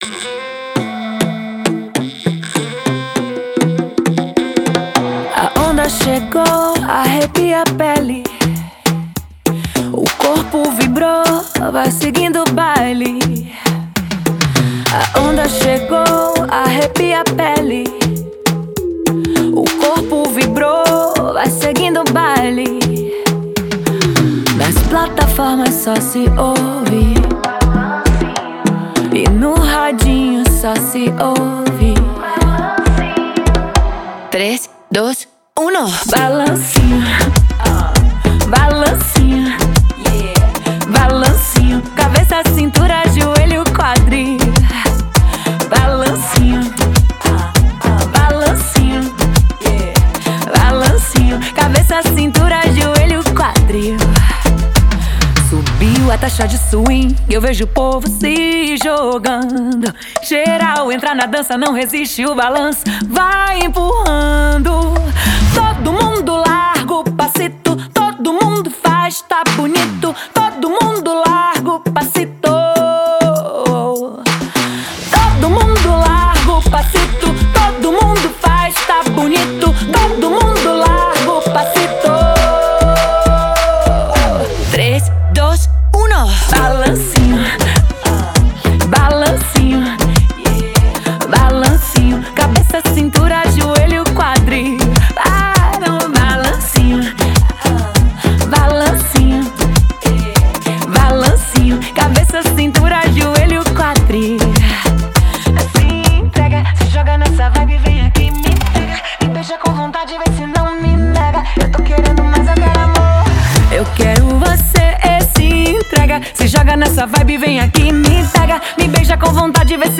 Genre: Pop